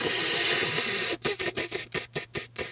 snake_2_hiss_02.wav